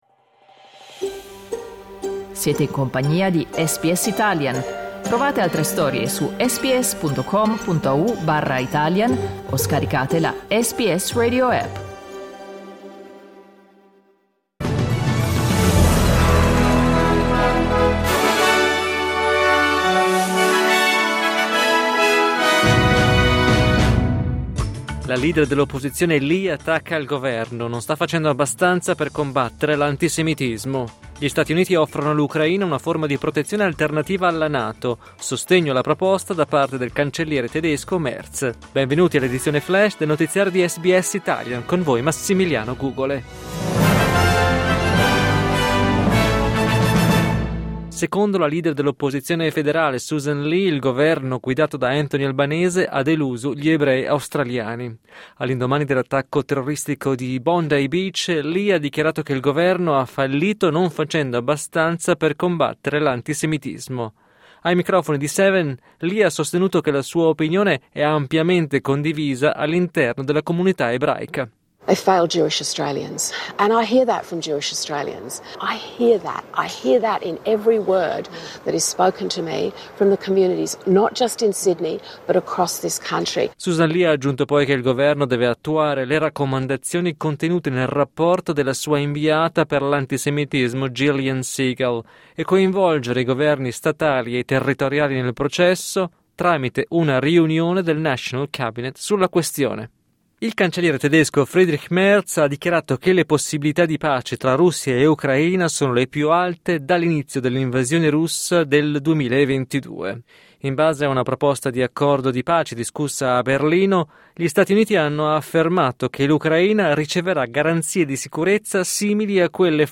News flash martedì 16 dicembre 2025